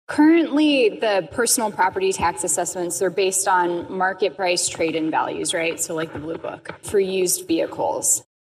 News Brief
At issue is how county assessors valuate a car or truck’s worth. During discussion, Senator Lauren Arthur mentioned this legislation would change the method to measure a vehicle’s trade-in value: